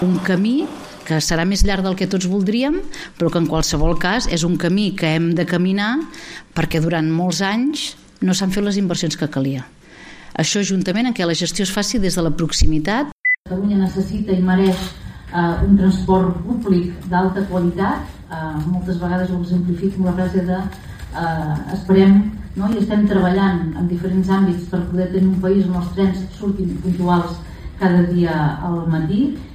La portaveu del Govern i consellera de Territori, Sílvia Paneque, n’informava ahir després de la reunió del Consell Executiu, reconeixia que serà un procés llarg però que la gestió directe des de Catalunya persegueix l’objectiu de tenir el millor servei.